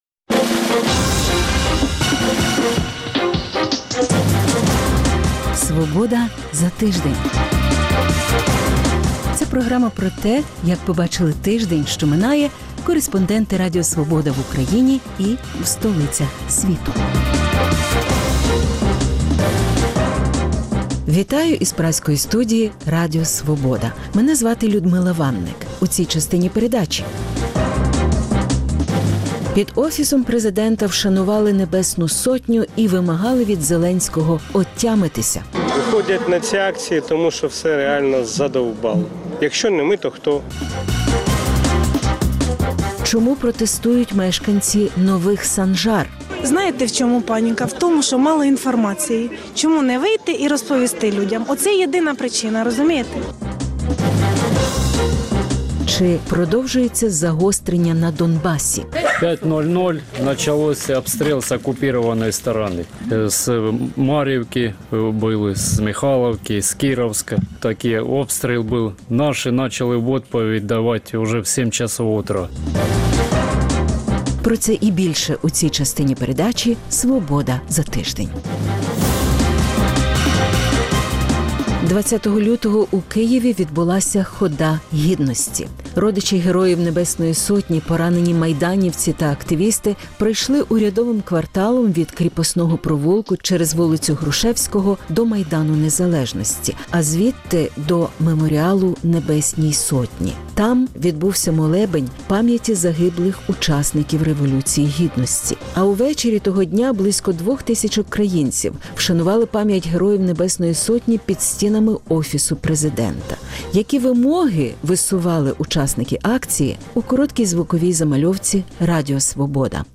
Знову Трамп чи демократи — хто бореться за посаду президента США? Інтерв'ю зі Стасом Асєєвим – автором Радіо Свобода, який 2 з половиною роки провів у полоні в бойовиків